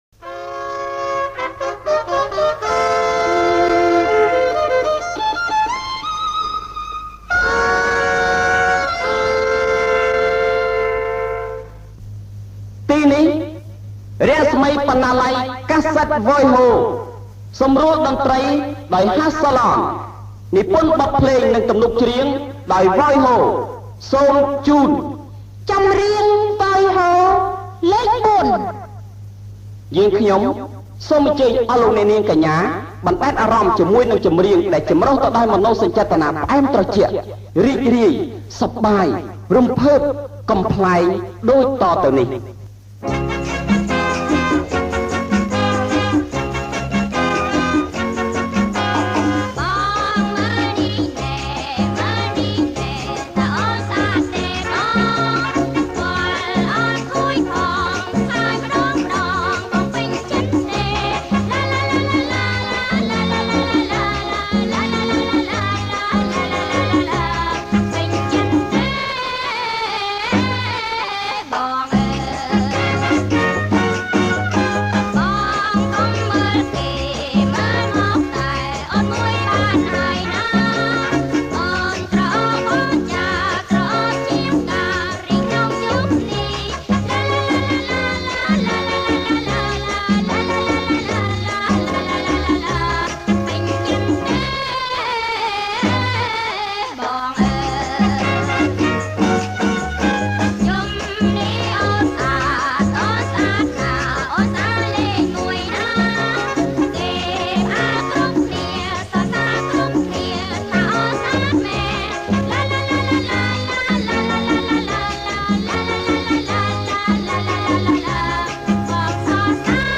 • ប្រគំជាចង្វាក់ Cha Cha Cha